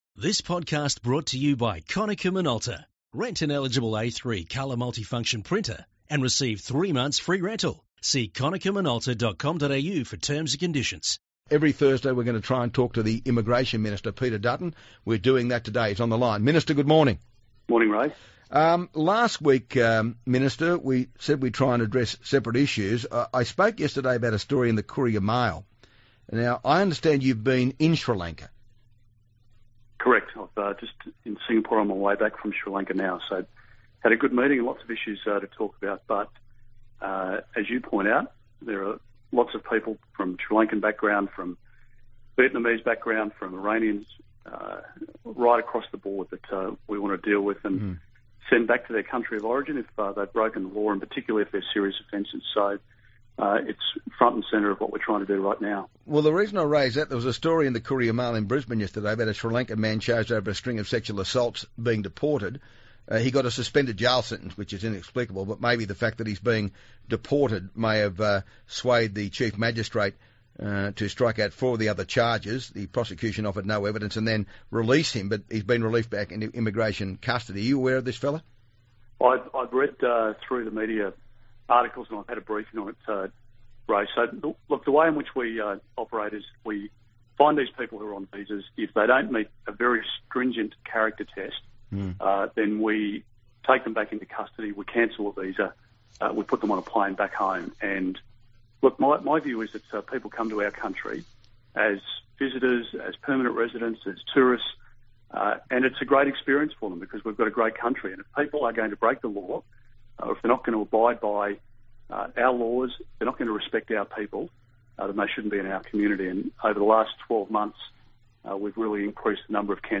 Immigration Minister Peter Dutton talks to Ray from Singapore about Sri Lankan asylum seekers, a criminal being deported to Sri Lanka, foreign farm workers being exploited and his Brisbane electorate